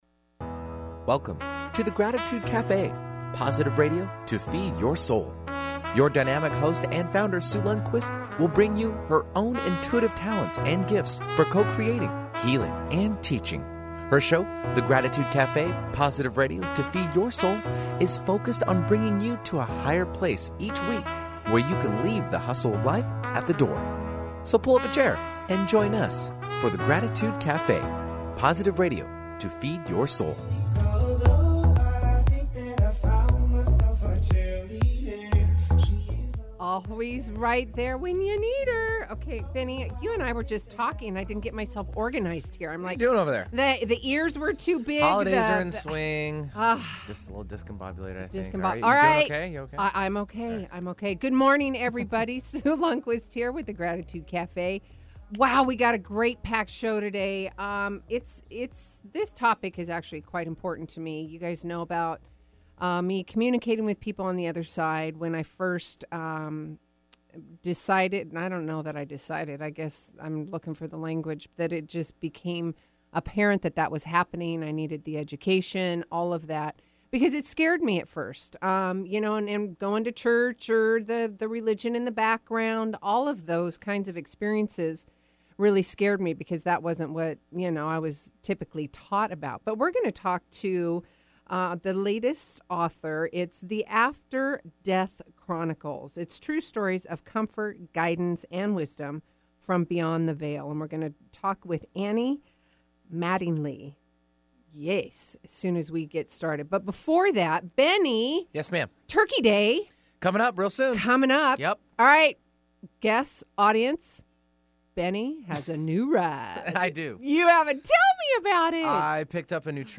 INTERVIEWS: ONLINE RADIO AND PODCASTS Please click on the links below to hear these interviews and conversations.